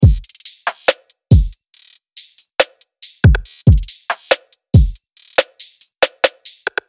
Download Trap sound effect for free.
Trap